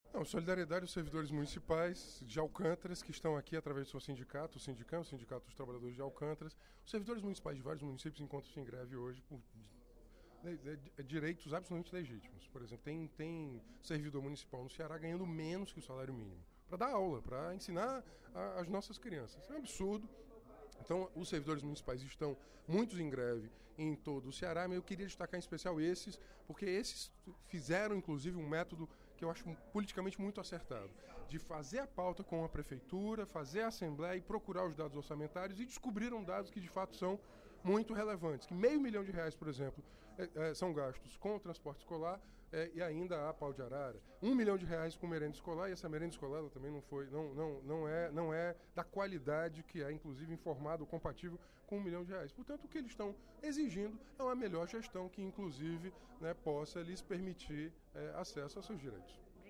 O deputado Renato Roseno (Psol) defendeu, nesta quarta-feira (01/07), durante o primeiro expediente da sessão plenária, a luta dos servidores públicos municipais do Ceará.